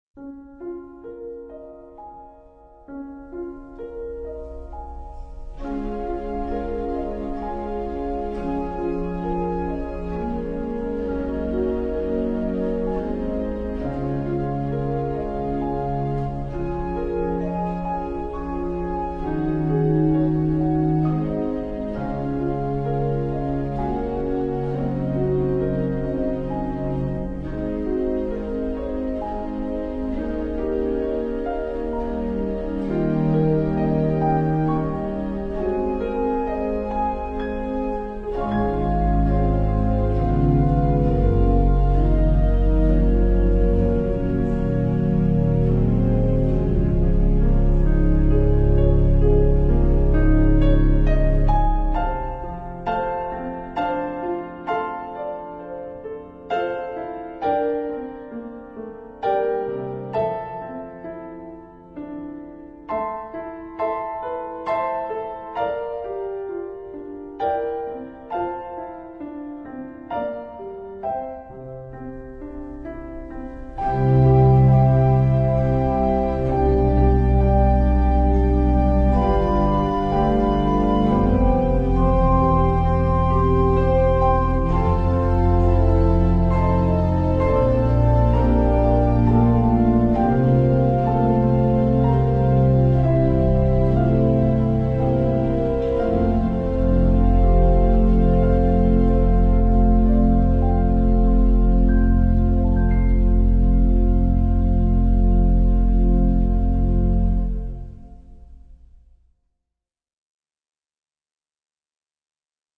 Voicing: Piano and Organ